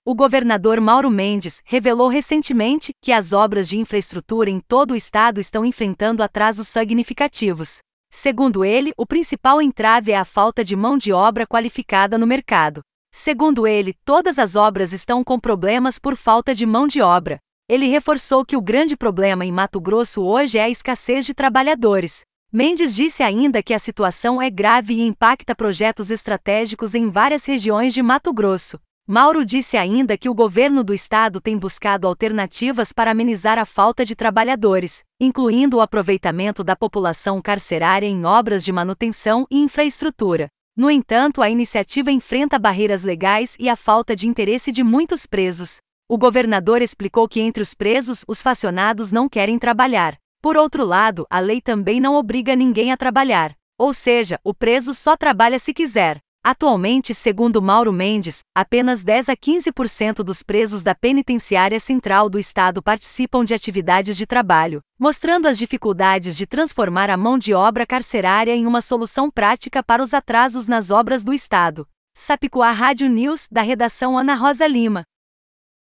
Boletins de MT 07 out, 2025